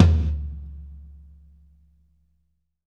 Index of /90_sSampleCDs/Sampleheads - New York City Drumworks VOL-1/Partition A/KD TOMS
FLOORGATE -R.wav